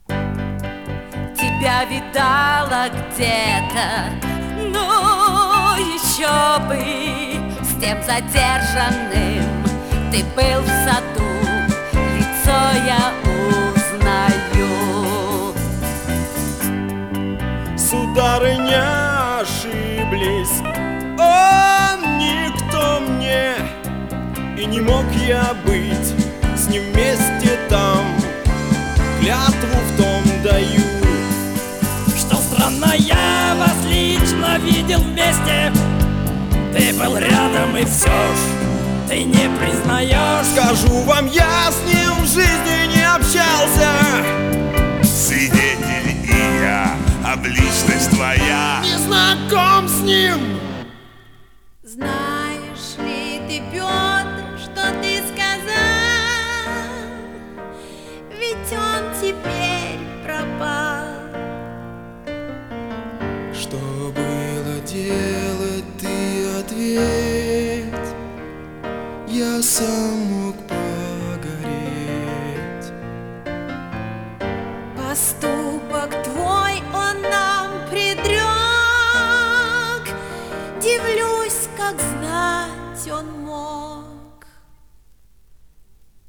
Жанр: Рок-опера
Формат: Vinil, 2 x LP, Stereo, Album
Стиль: Вокал